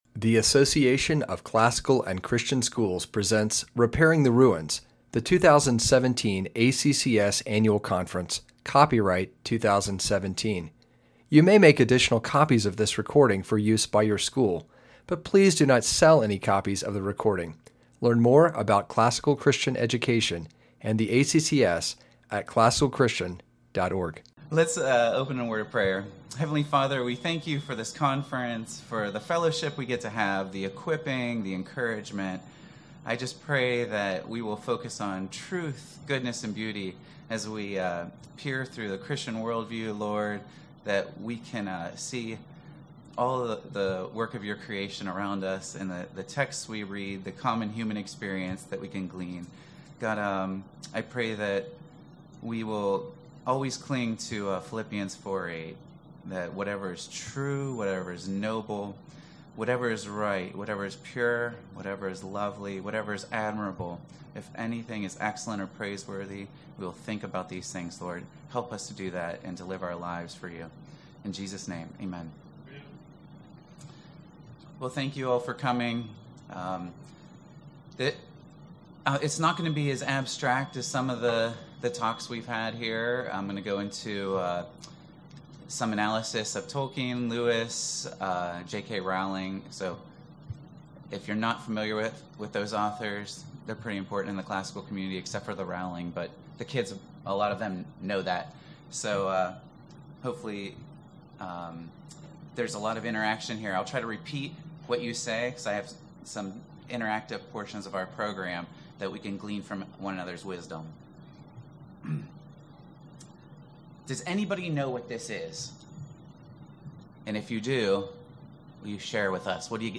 2017 Workshop Talk | 1:03:03 | All Grade Levels, General Classroom, Math